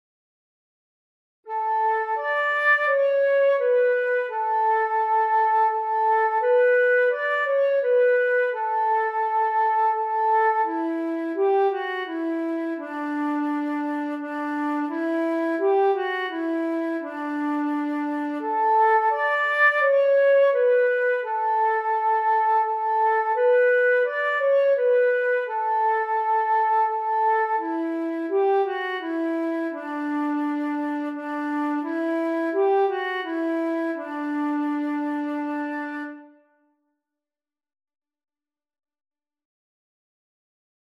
Eenstemmig - hoge en lage versie
Langzaam, verheven
Lager (in C)